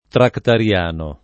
vai all'elenco alfabetico delle voci ingrandisci il carattere 100% rimpicciolisci il carattere stampa invia tramite posta elettronica codividi su Facebook tractariano [ traktar L# no ] o trattariano [ trattar L# no ] agg. e s. m. (eccl.)